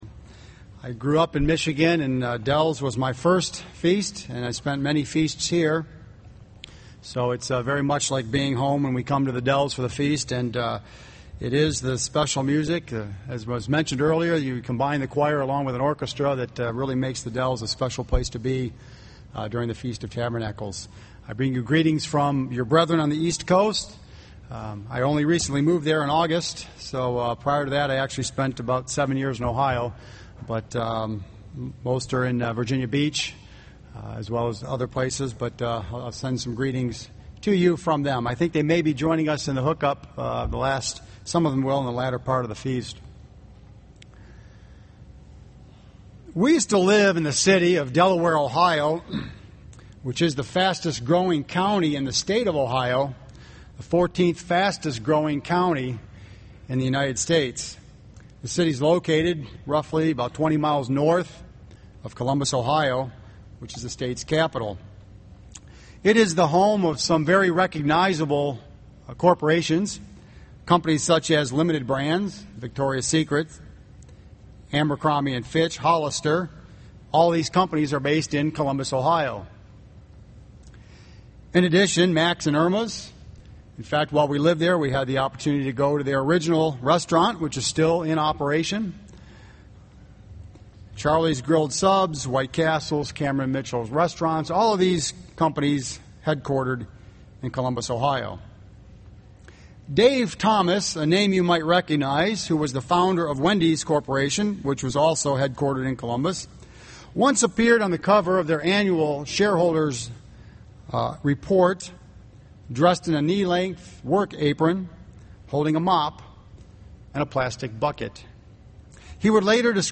This sermon was given at the Wisconsin Dells, Wisconsin 2011 Feast site.